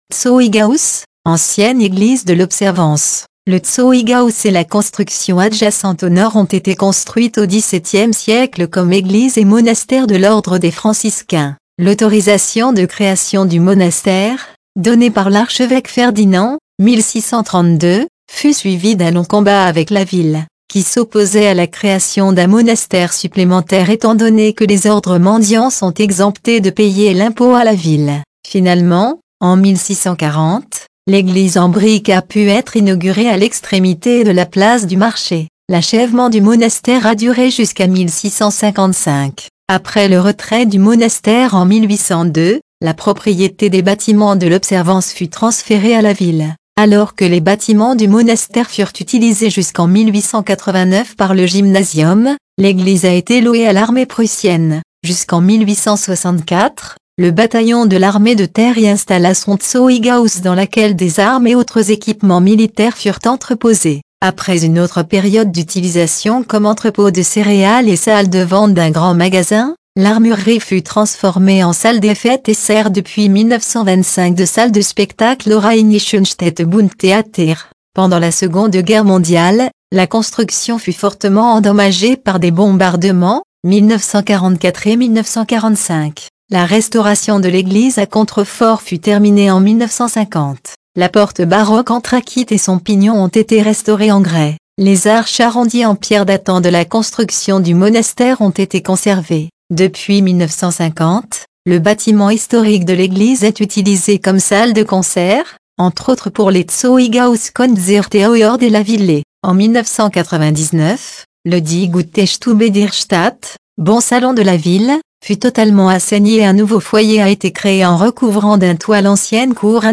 Audio Guide Français